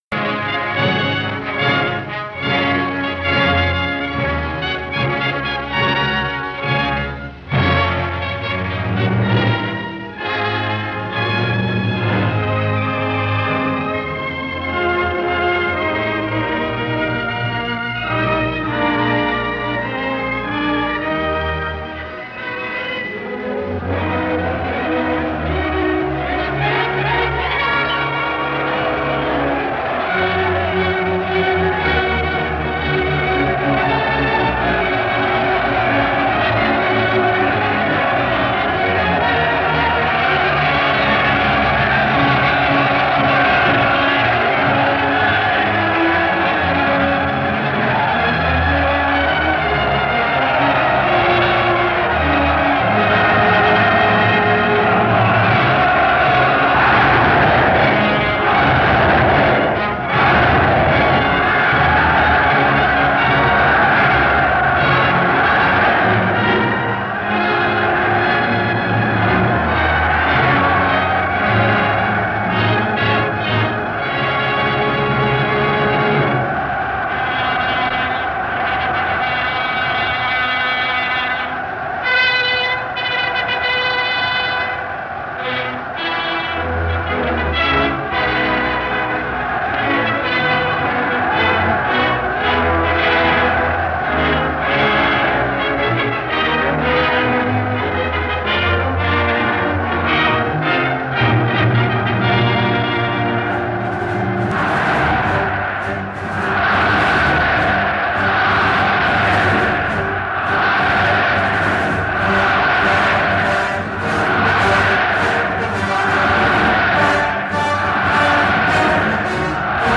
Audio excerpts from Donald J. Trump are mixed with clips from "Citizen Kane," "Network," "Dr. Strangelove," "Triumph of the Will," "Star Wars," and "All in the Family."